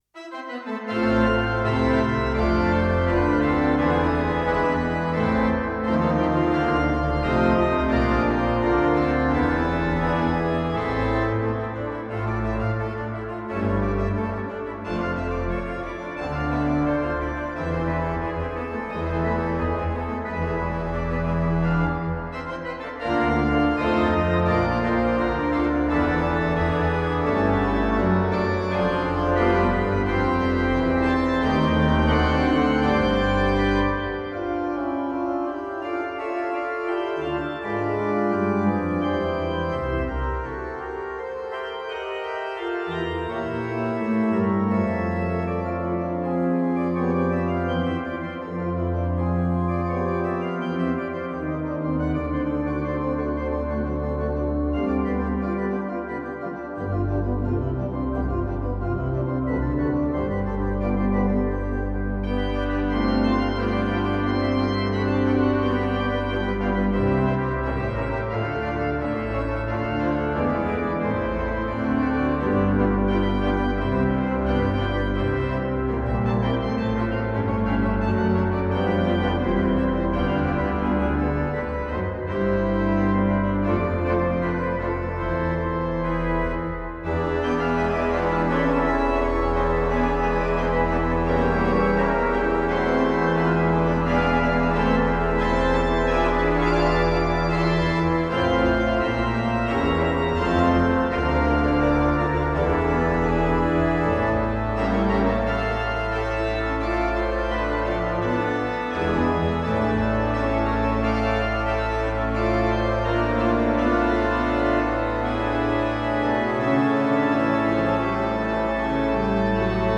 It is a bright and festive work, offering plenty of freedom to choose the registration according to your own musical sense and taste.